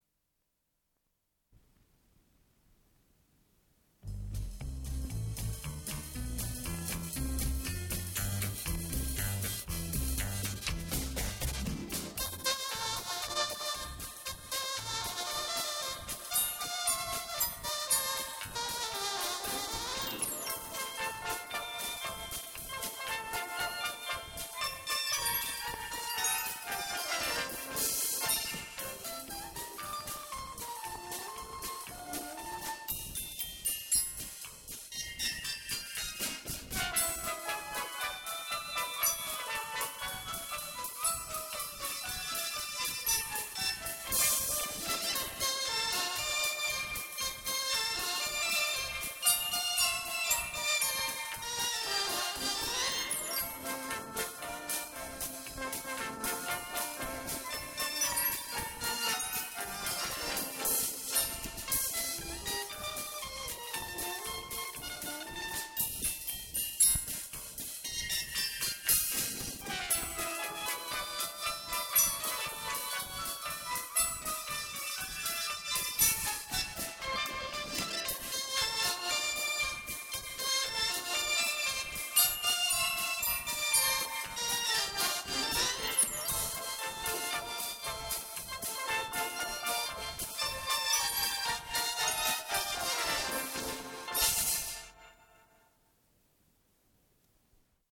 Дубль моно.